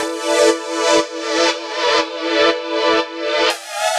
Index of /musicradar/french-house-chillout-samples/120bpm
FHC_Pad B_120-E.wav